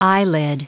Prononciation du mot eyelid en anglais (fichier audio)
Prononciation du mot : eyelid